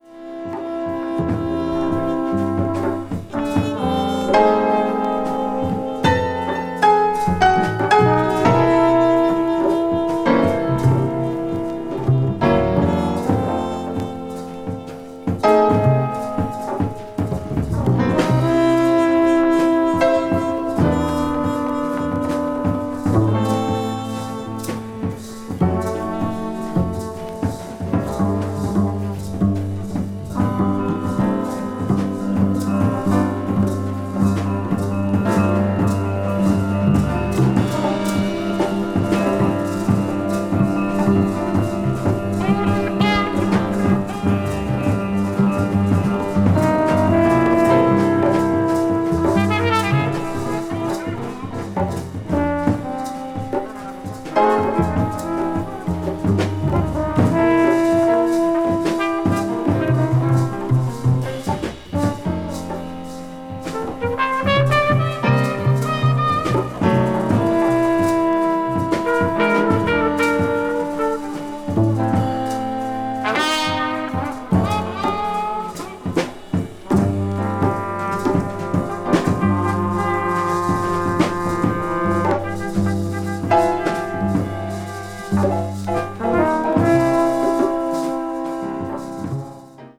media : EX/EX(some slightly noise.)
Recorded in Paris that same year
leading into Side A—a smoky, slow-grooving piece.
avant-jazz   free jazz   post bop   spiritual jazz